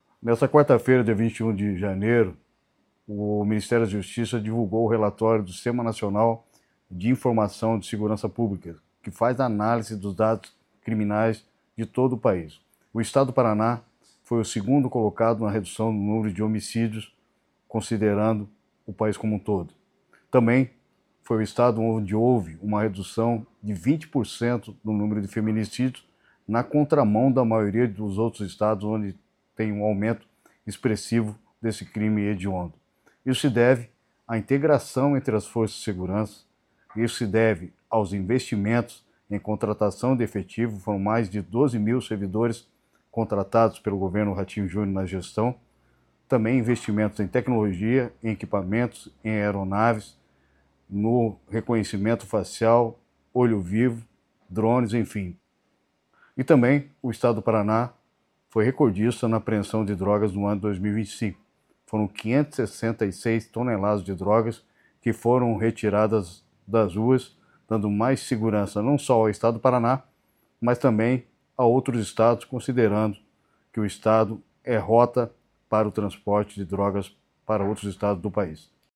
Sonora do secretário da Segurança Pública, Hudson Leôncio Teixeira, sobre a redução no número de homicídios no Paraná